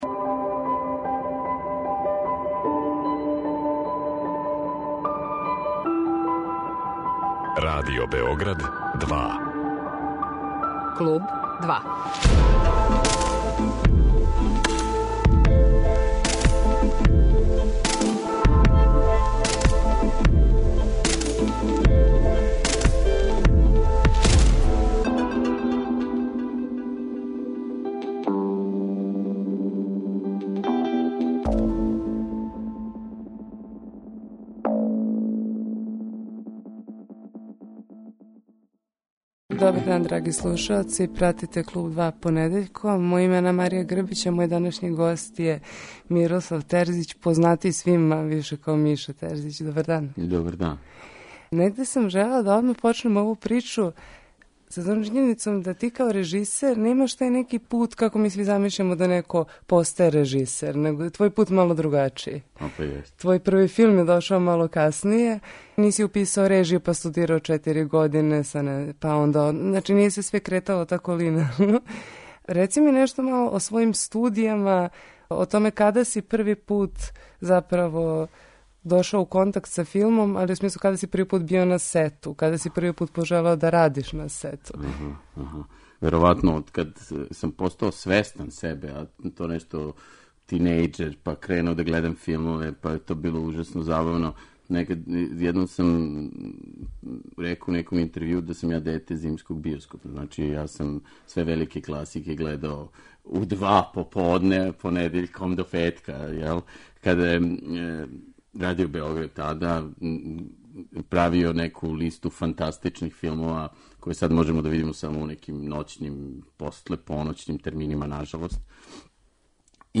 Данашњи гост емисије Клуб 2 је режисер Мирослав Терзић. Са Мирославом разговарамо о његовом филму Устаничка улица али и о најновијем кратком филму као и о новом дугометражном филму Шавови који нас следеће године очекује у биоскопима.